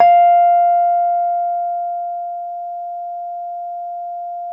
RHODES CL0GL.wav